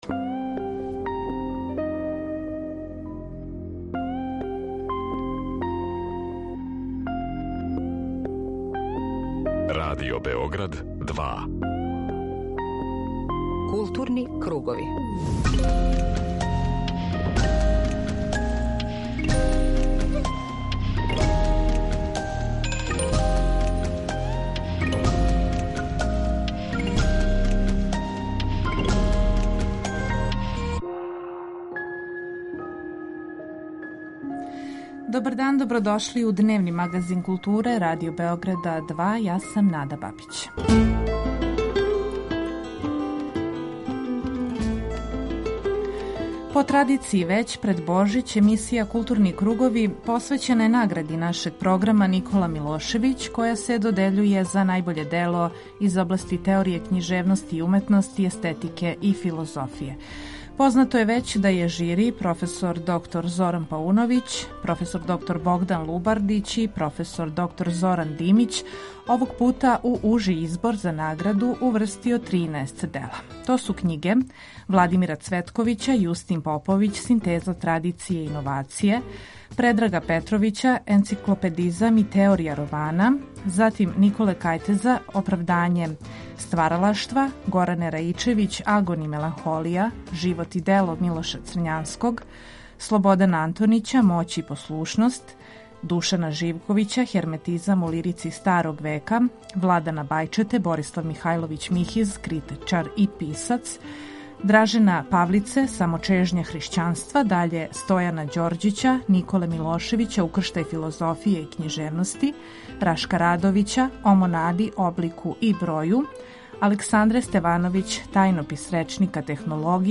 Чућемо данас у емисији и реч жирија о овогодишњој продукцији књига приспелих на конкурс за награду.